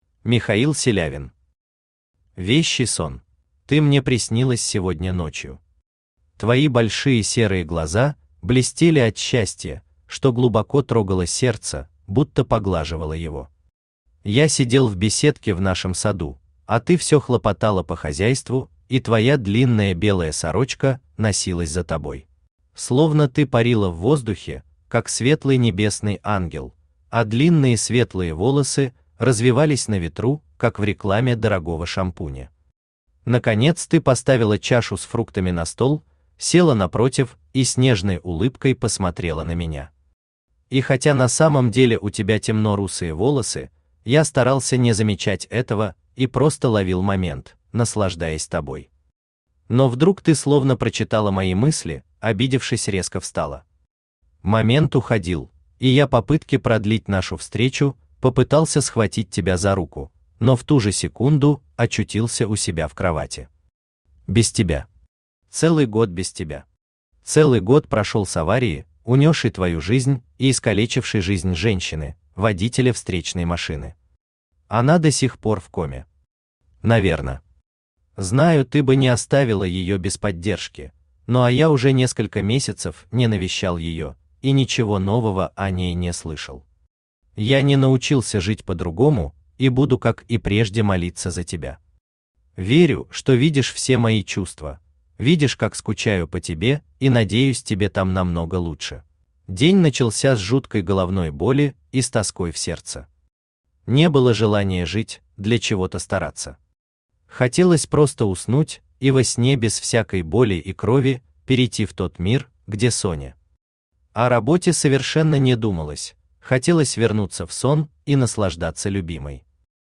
Aудиокнига Вещий сон Автор Михаил Анатольевич Селявин Читает аудиокнигу Авточтец ЛитРес.